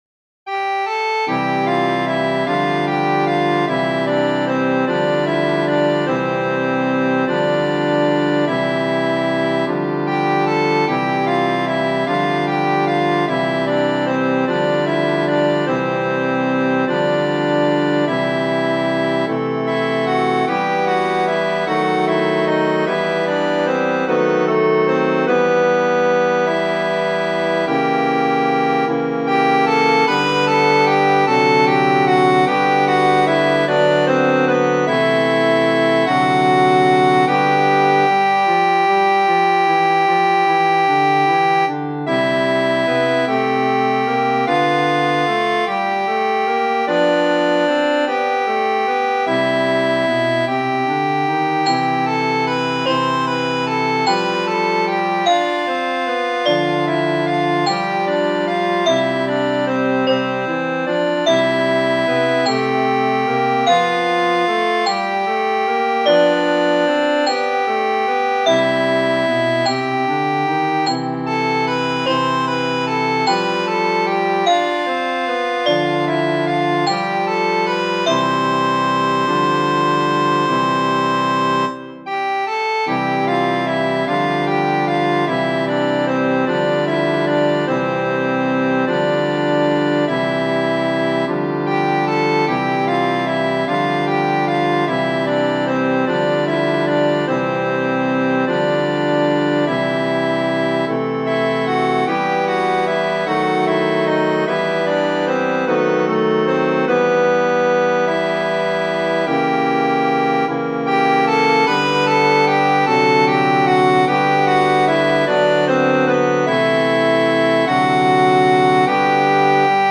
Genere: Religiose
è una pastorale natalizia